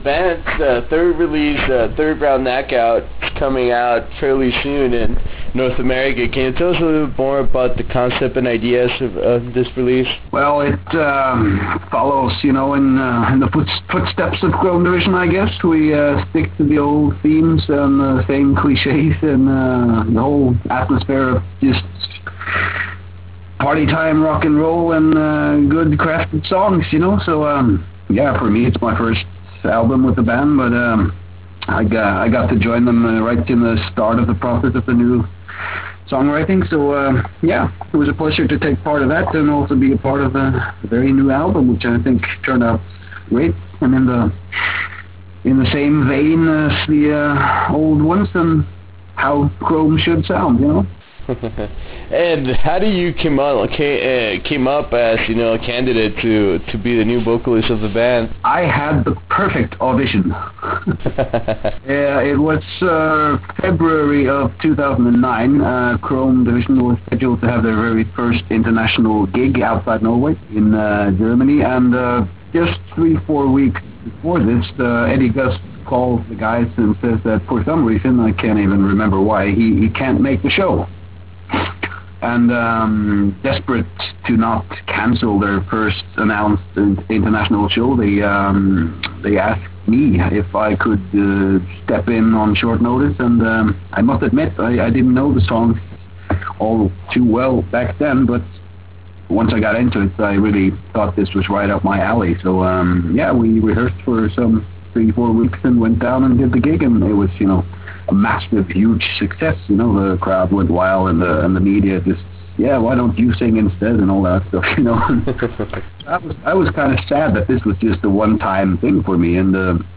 In this interview we discuss the party atmosphere behind this very entertaining group that features Shagrath of Dimmu Borgir fame.